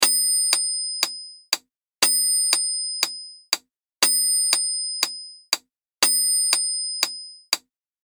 メトロノームBPM120を4小節.mp3